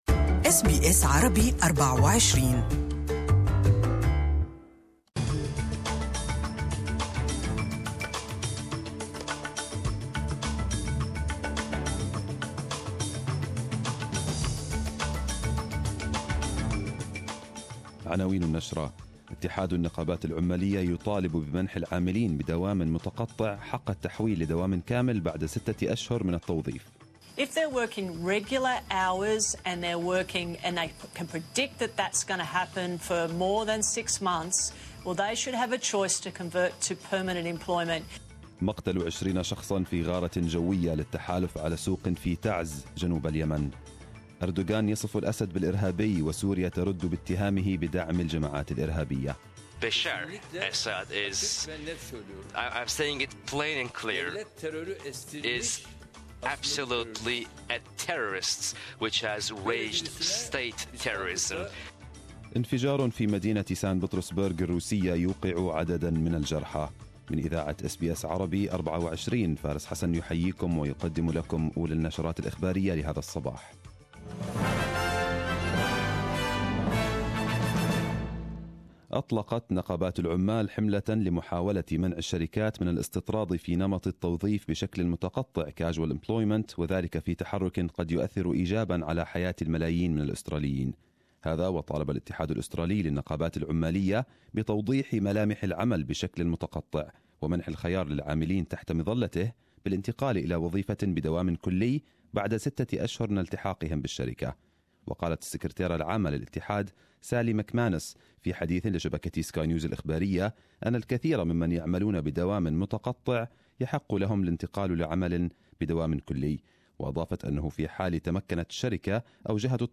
Arabic News Bulletin 28/12/2017